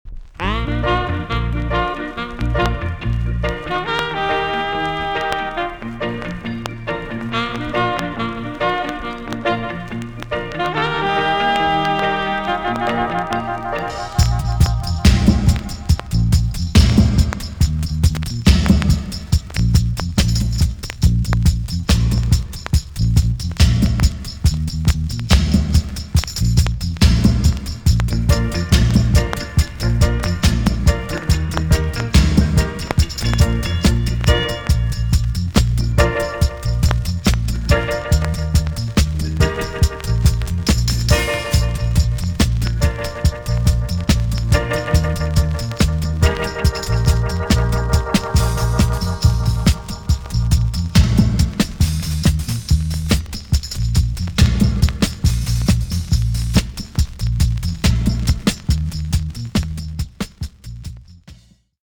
TOP >REGGAE & ROOTS
B.SIDE Version
EX-~VG+ 少し軽いプチノイズが入ります。